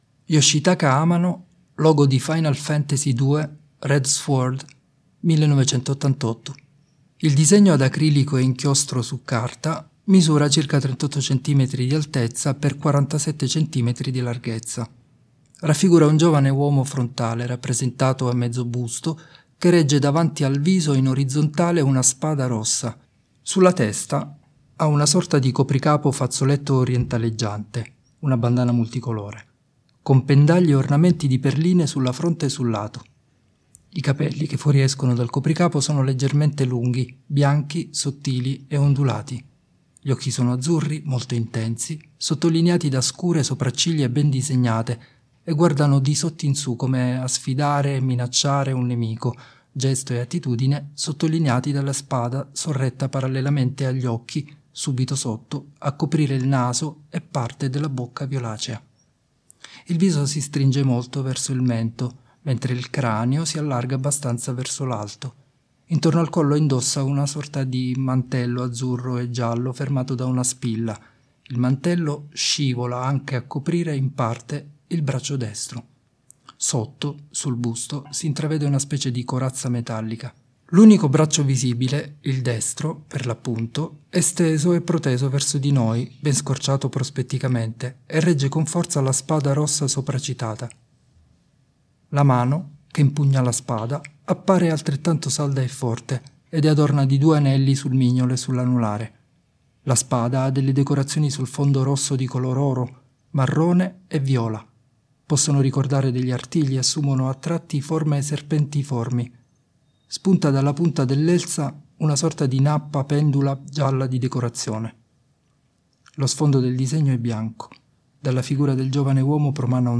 Descrizioni pannelli sensoriali per ciechi e ipo-vedenti: